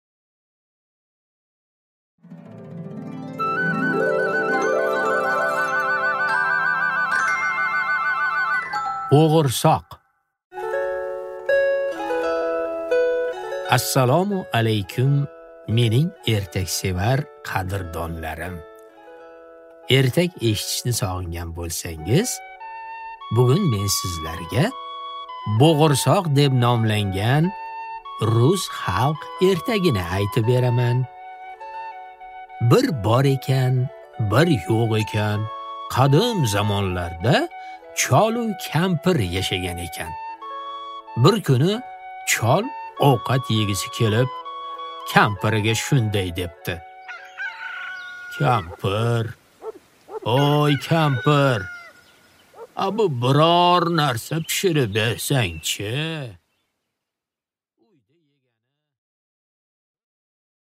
Аудиокнига Bo'g’irsoq | Библиотека аудиокниг